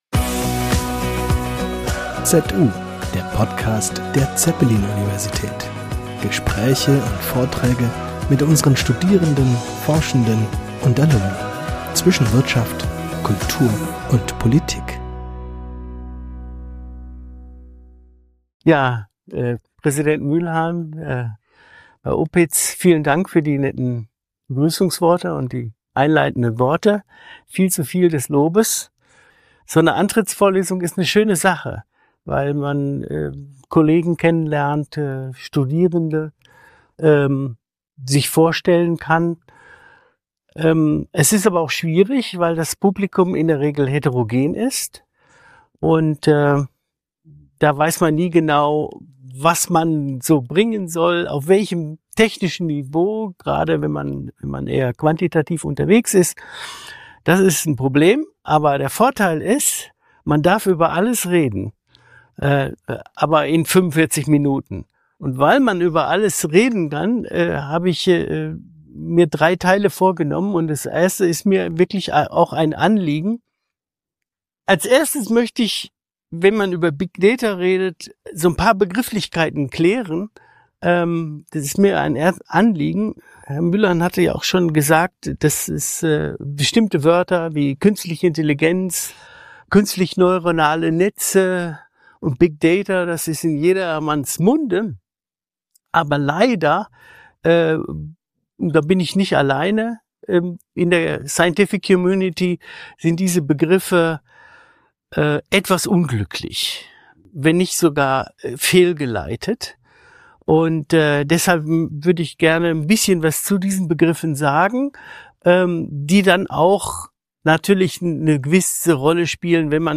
Der Vortrag beleuchtet die Frage, ob Big Data tatsächlich zwangsläufig zu besseren Entscheidungen führt oder ob der Umgang mit großen Datenmengen neue Herausforderungen mit sich bringt, die eine datenbasierte Entscheidungsfindung in Wissenschaft und Wirtschaft erschweren. Anhand von Beispielen aus der Finanzmarktforschung wird gezeigt, wie diese Herausforderungen durch den Einsatz neuer Methoden des maschinellen Lernens bewältigt werden können.